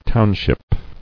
[town·ship]